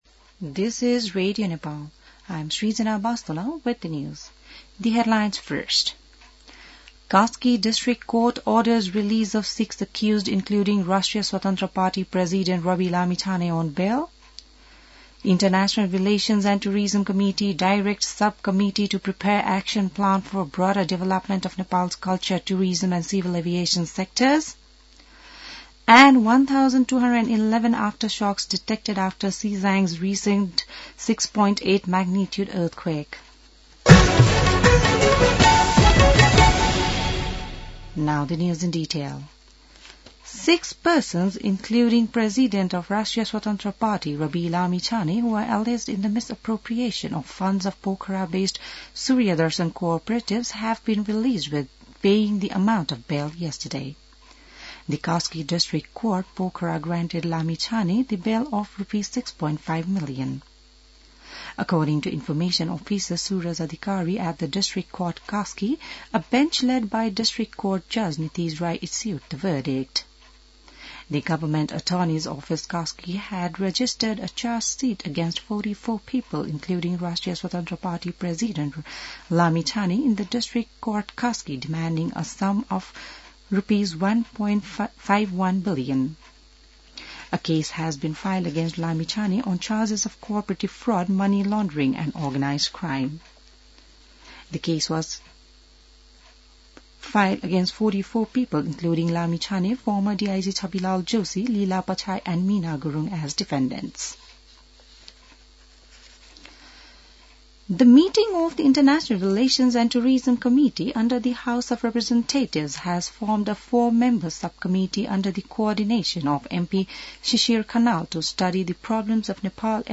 बिहान ८ बजेको अङ्ग्रेजी समाचार : २७ पुष , २०८१